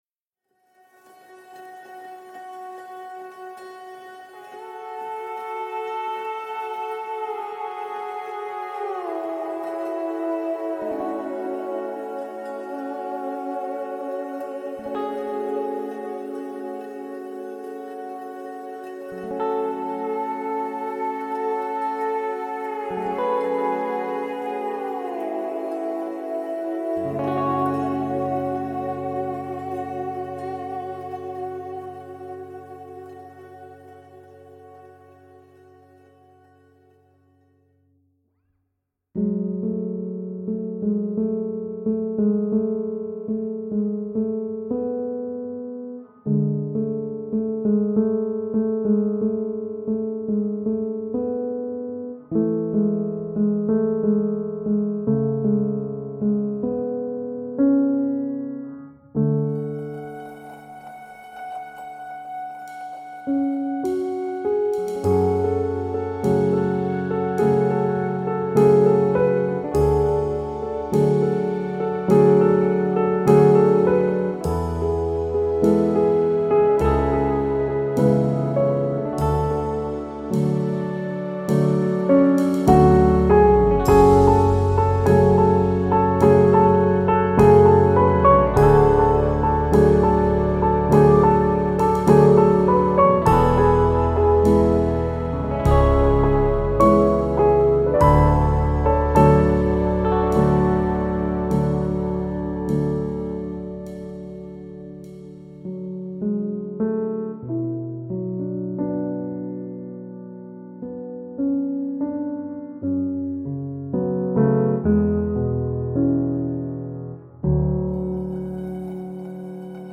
• آهنگ بی کلام